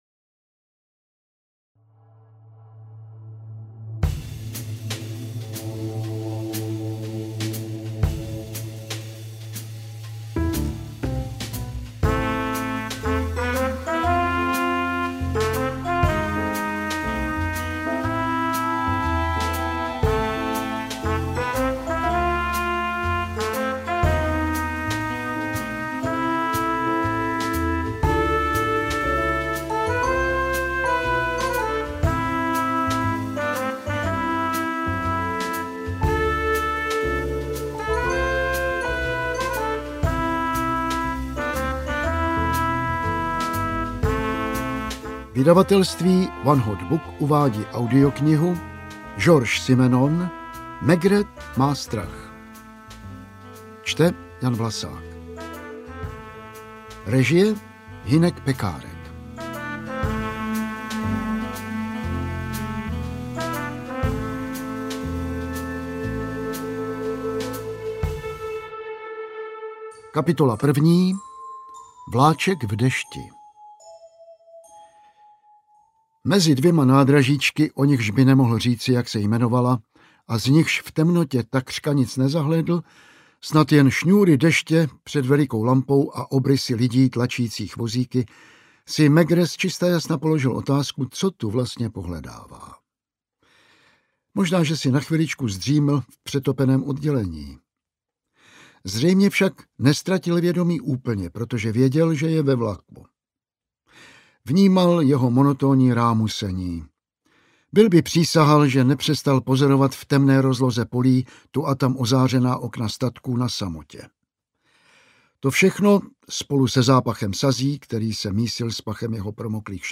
Interpret:  Jan Vlasák